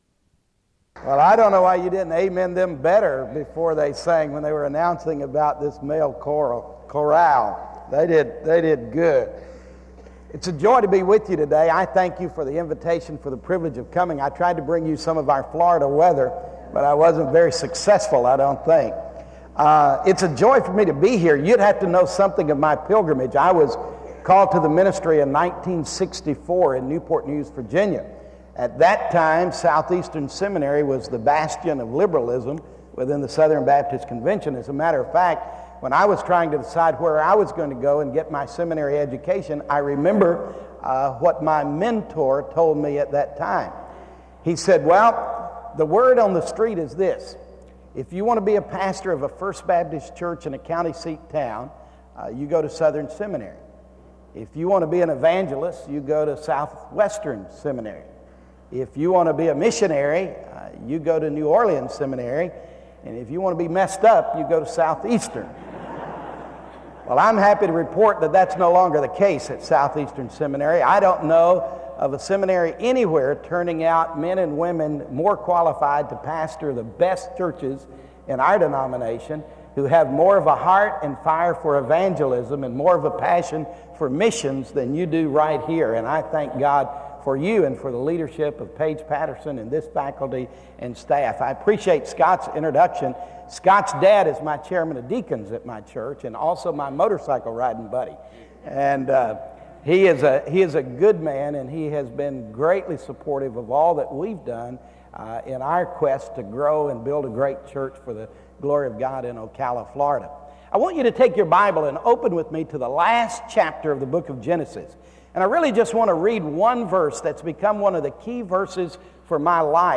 SEBTS Chapel and Special Event Recordings - 2000s